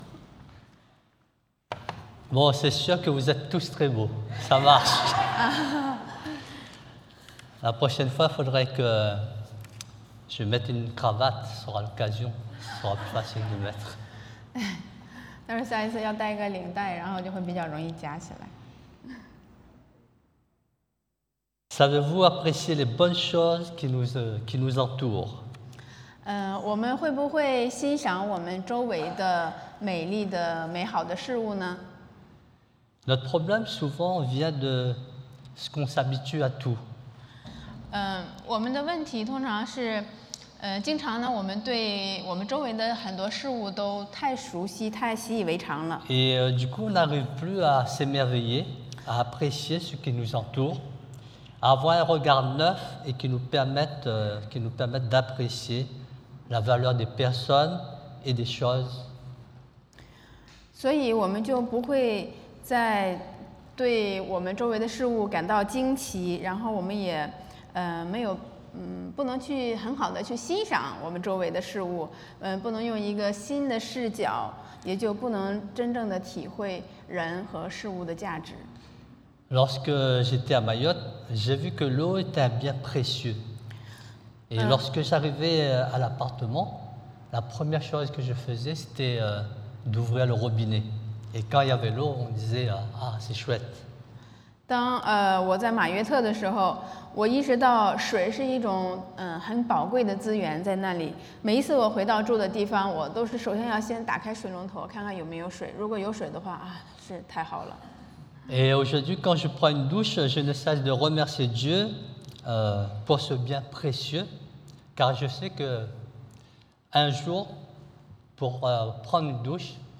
Passage: Matthieu 马太福音 8:5-11 Type De Service: Predication du dimanche